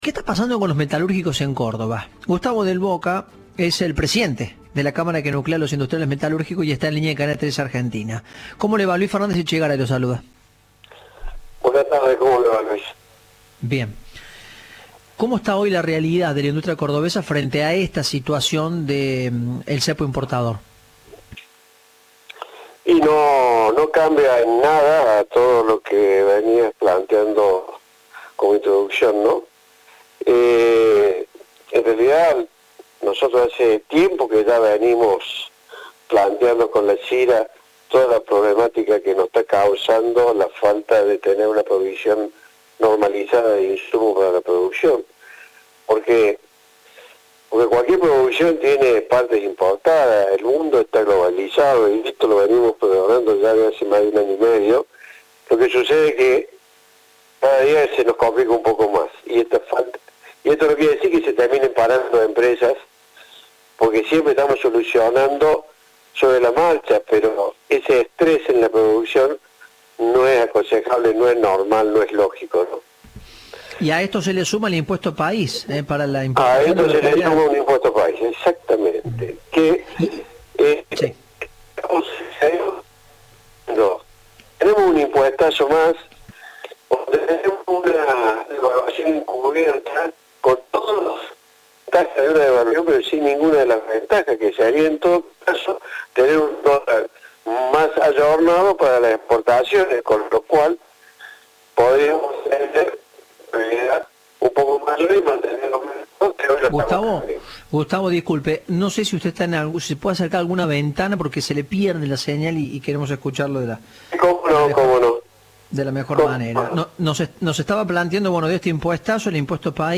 Entrevista "Informados, al regreso".